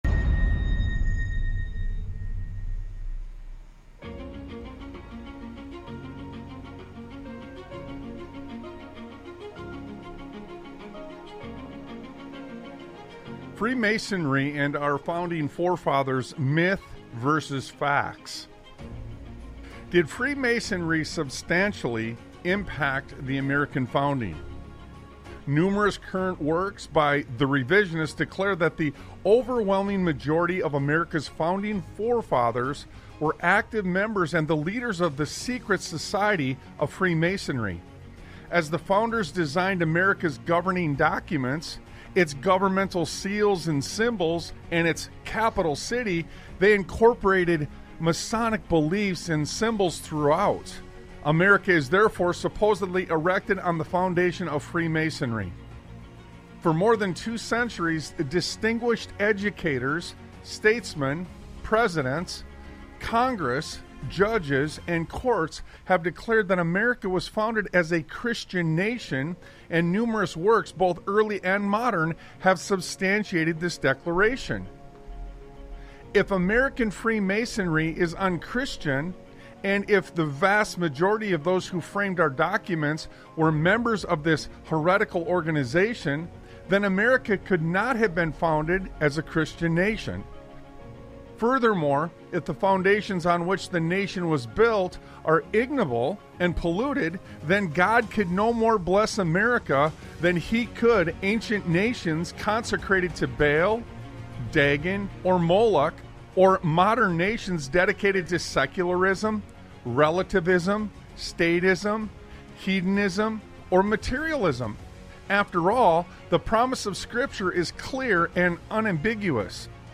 Talk Show Episode, Audio Podcast, Sons of Liberty Radio and The Caller Made Some Good Points Yet, They Weren't Correct on , show guests , about The Caller Made Some Good Points Yet,They Weren't Correct,The Christian Foundation of America,Debunking the Masonic Myth,Reclaiming the Christian intellectual heritage,American Founding Fathers,Webster’s 1828 Dictionary,Original Founding Documents,Stolen Valor of Freemasonry,Modern Masonry, categorized as Education,History,Military,News,Politics & Government,Religion,Christianity,Society and Culture,Theory & Conspiracy